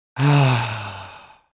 Sigh Male 01
Sigh Male 01.wav